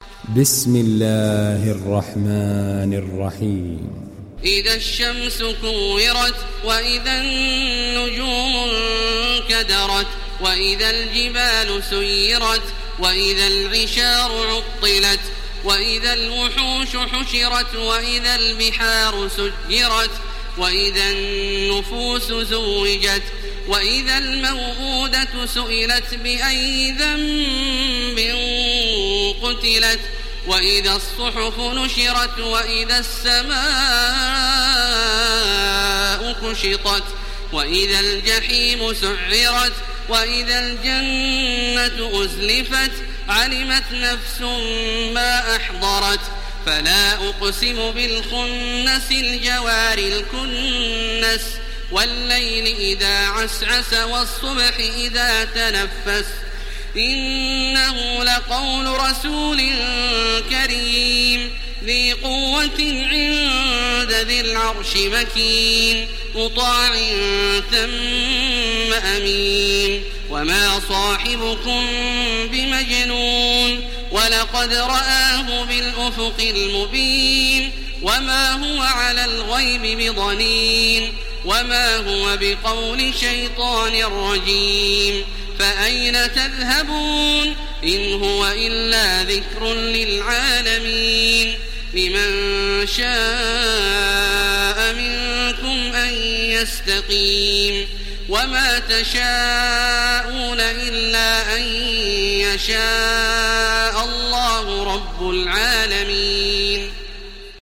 Télécharger Sourate At Takwir Taraweeh Makkah 1430